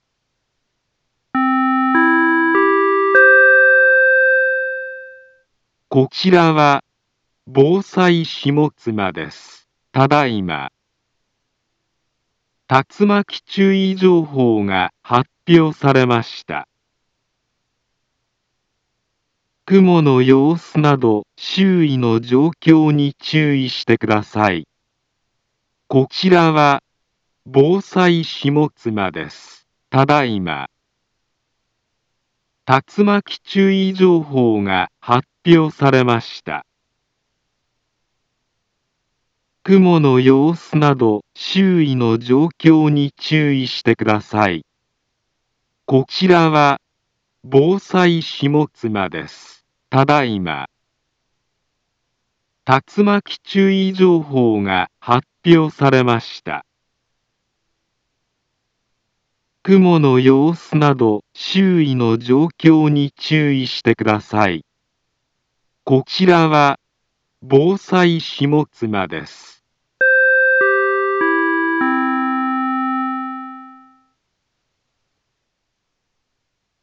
Back Home Ｊアラート情報 音声放送 再生 災害情報 カテゴリ：J-ALERT 登録日時：2022-08-13 20:59:41 インフォメーション：茨城県南部は、竜巻などの激しい突風が発生しやすい気象状況になっています。